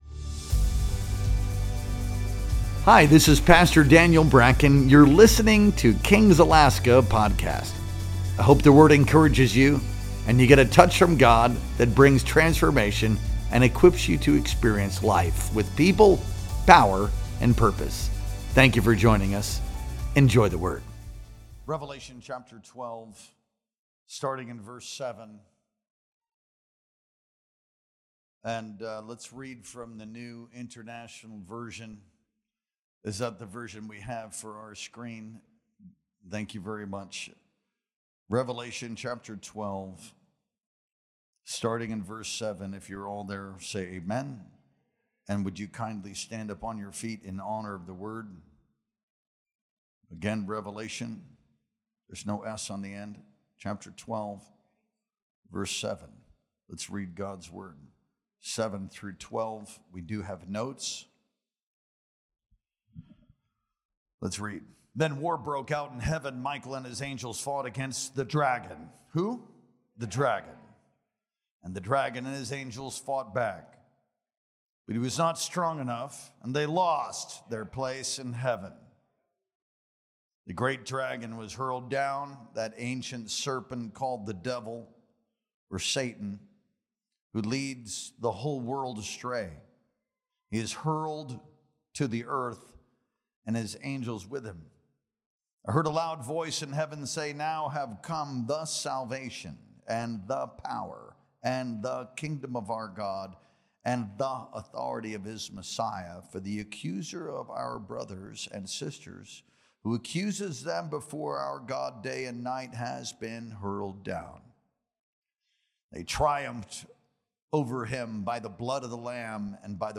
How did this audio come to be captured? Our Wednesday Night Worship Experience streamed live on October 3rd, 2025.